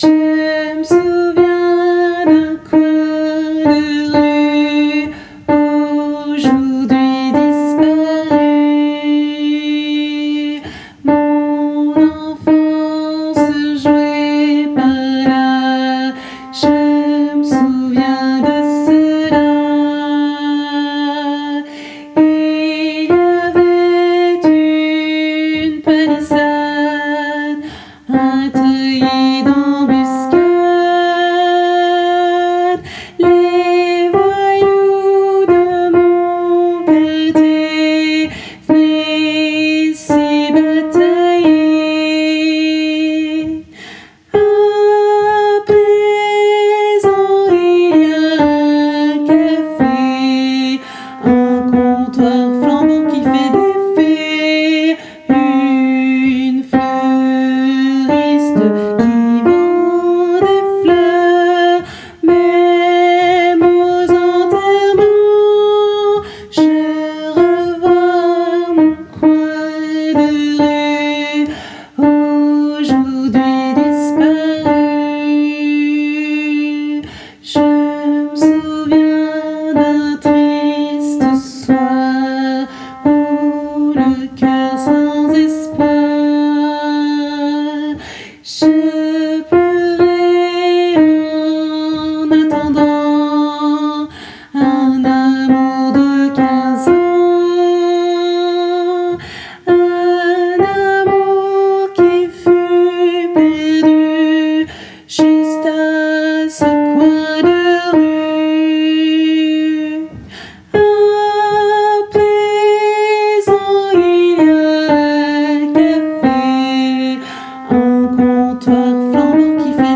Mp3 versions chantées